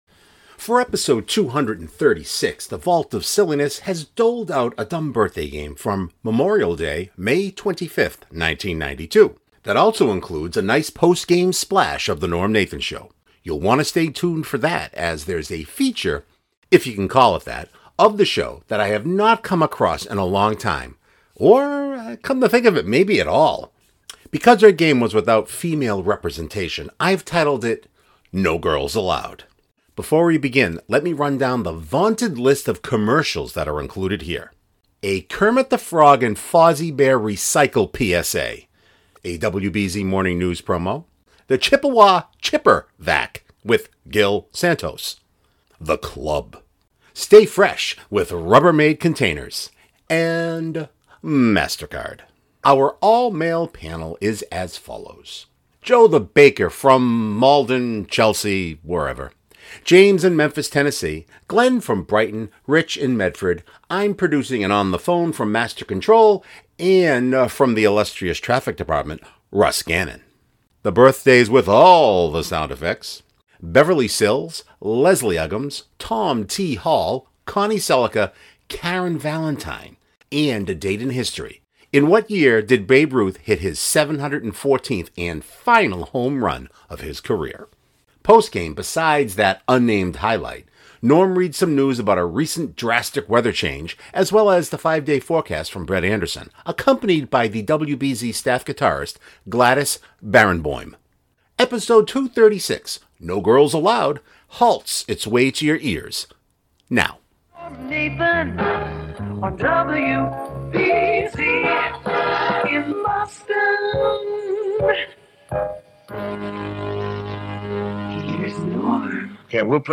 For Ep 236 the Vault of Silliness has doled out a DBG from Memorial Day, May 25th, 1992, that also includes a nice post-game splash of the NNS.
Our all-male panel is as follows: